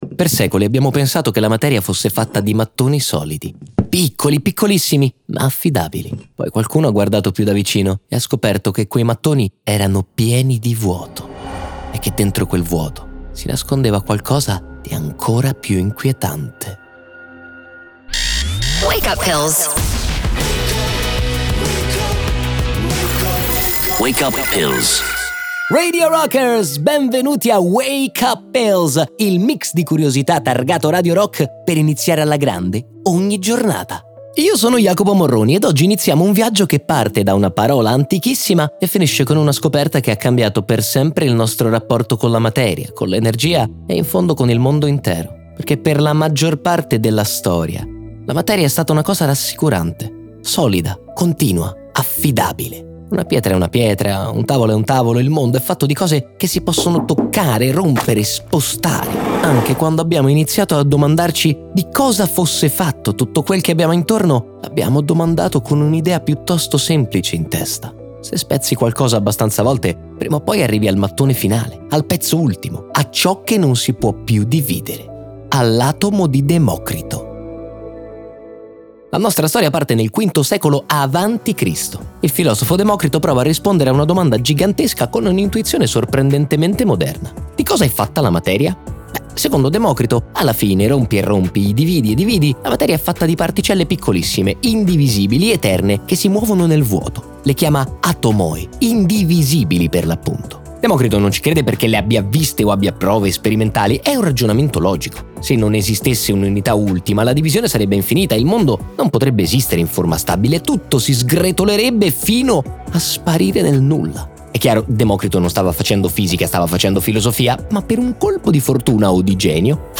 montaggio audio e sound design